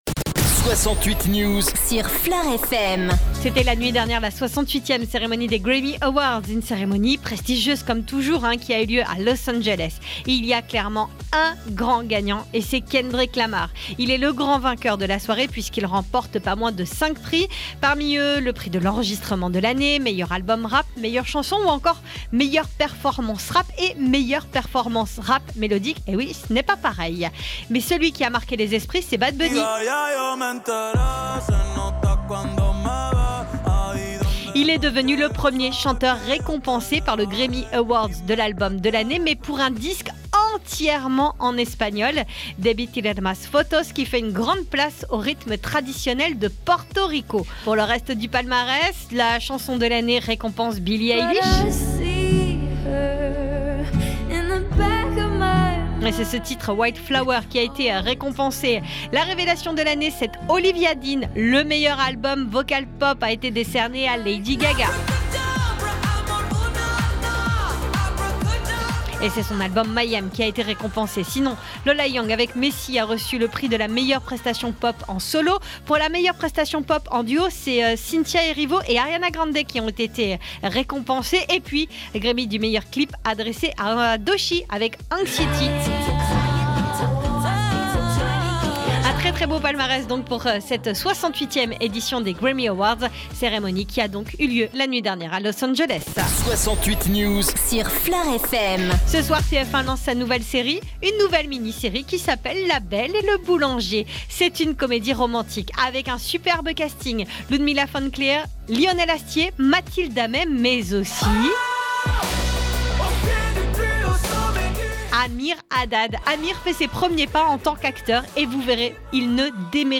FLOR FM : Réécoutez les flash infos et les différentes chroniques de votre radio⬦